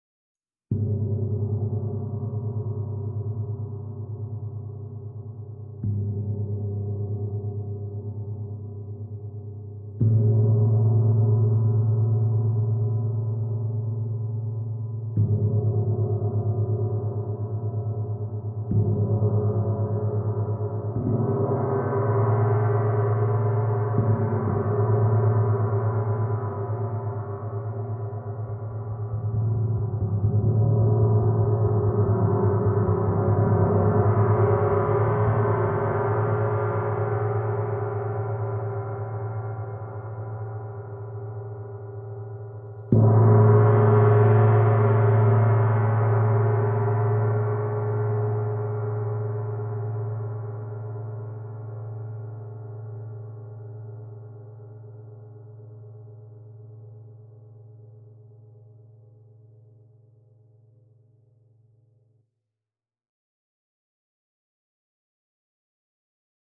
Meinl Sonic Energy 22" Soundscape Gong, Om Lotus (SGOML22)